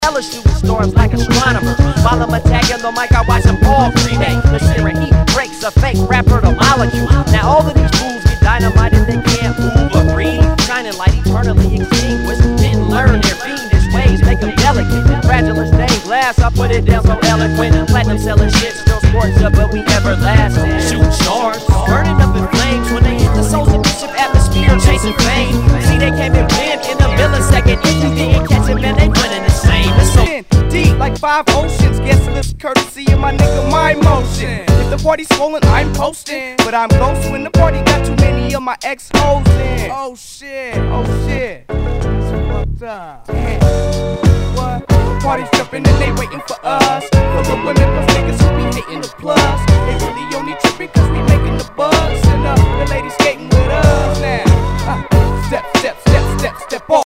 HIPHOP/R&B
ナイス！ヒップホップ！